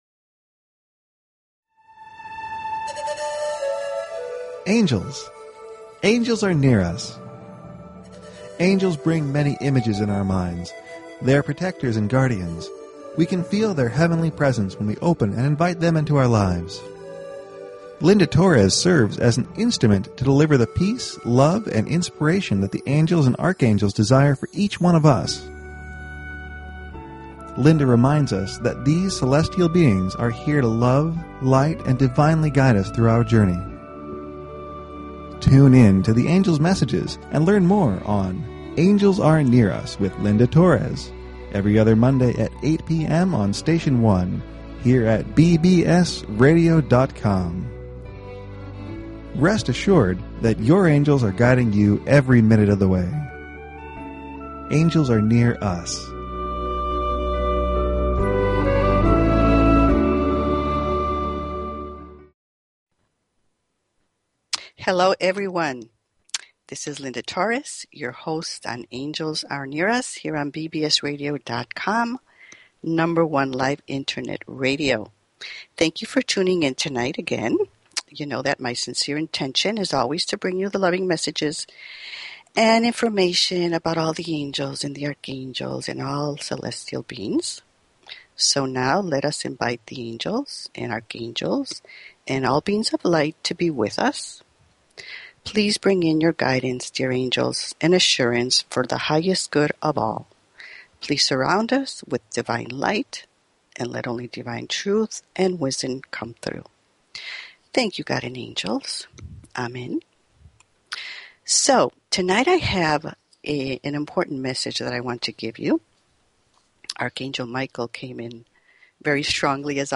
The last 30 minutes of the show the phone lines will be open for questions and Angel readings.